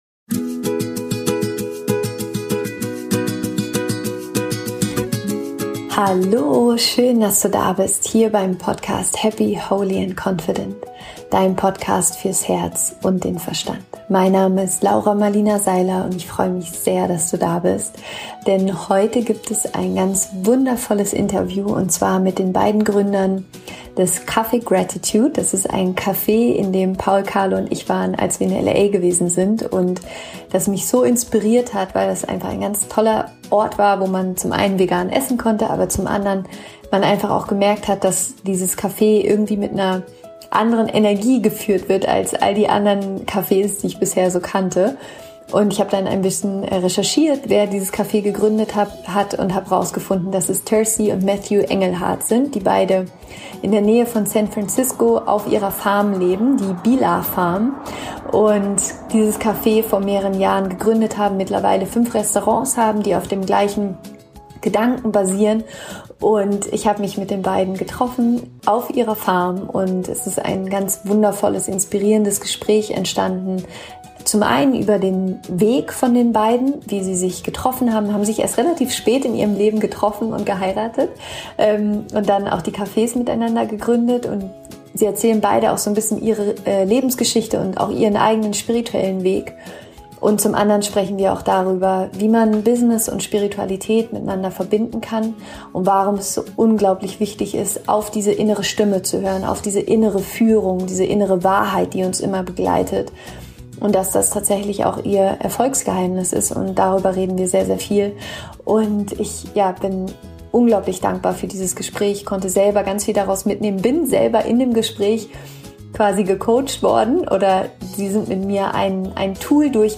** Das Interview ist auf Englisch.